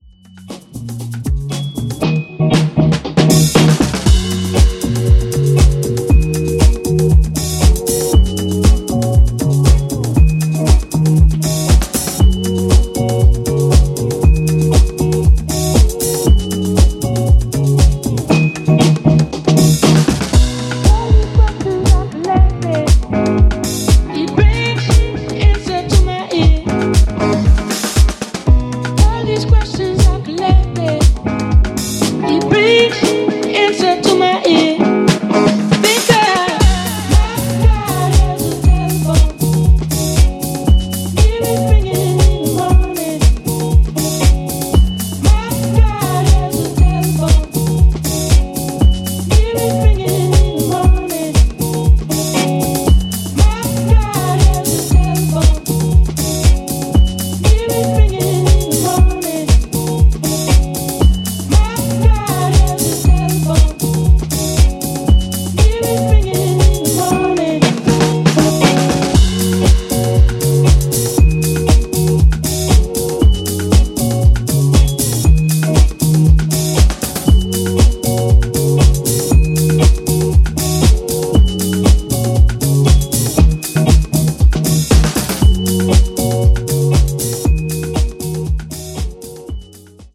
ジャンル(スタイル) NU DISCO / DISCO HOUSE / DEEP HOUSE